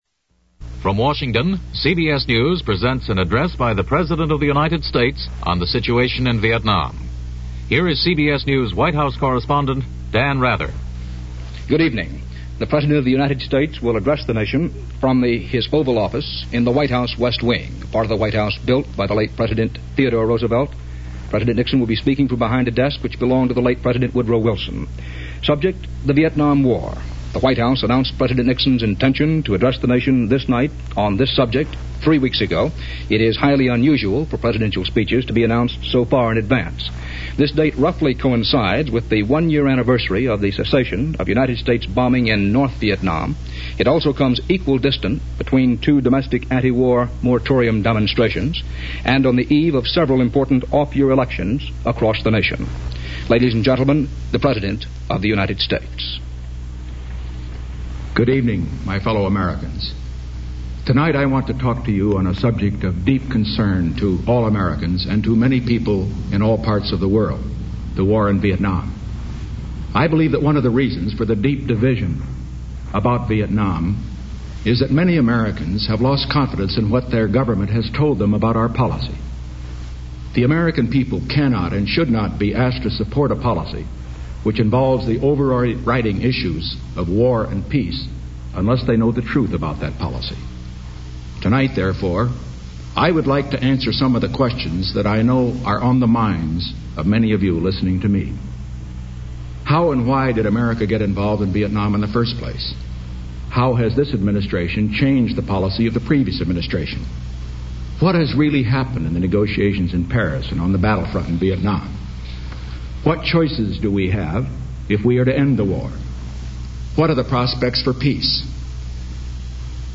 President Nixon's address to nation, November 3, 1969